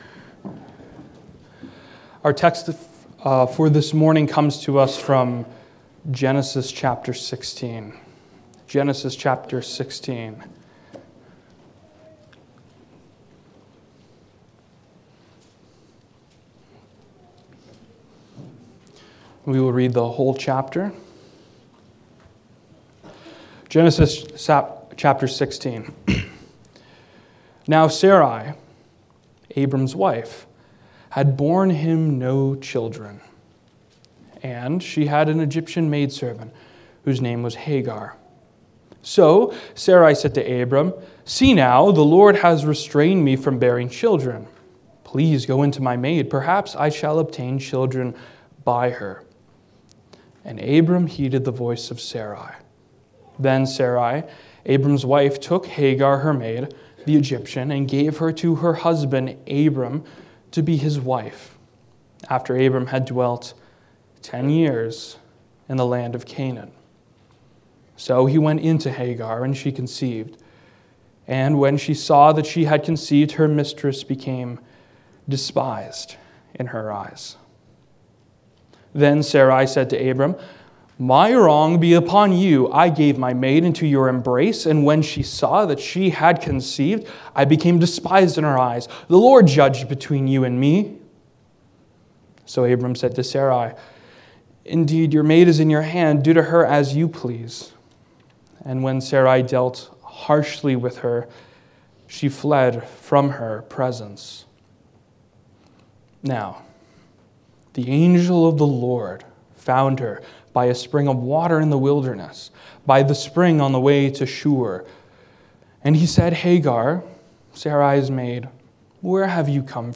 Passage: Genesis 16 Service Type: Sunday Morning